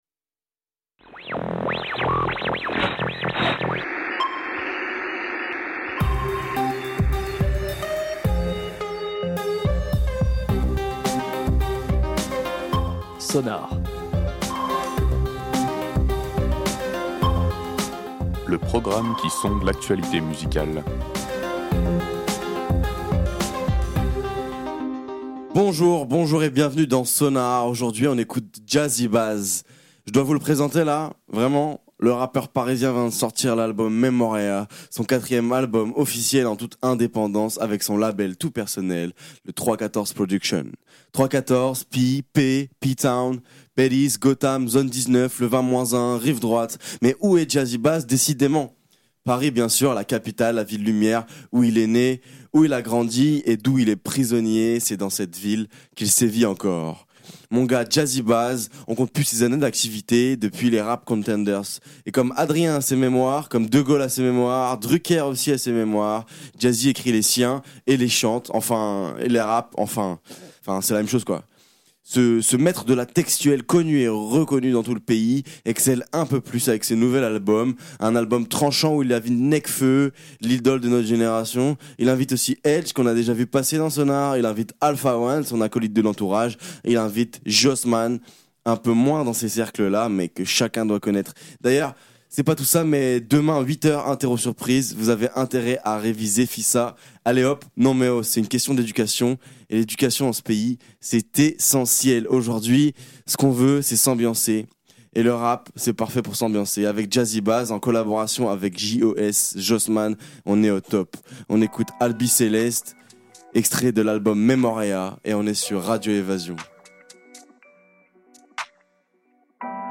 par | Fév 1, 2022 | Emissions, Sonar
Sur son propre label 3.14 records, Jazzy Bazz sort un album introspectif Memoria ! Un album de rap actuel, profond, dans lequel il envoie du style avec abondance.